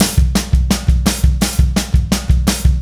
Indie Pop Beat 02.wav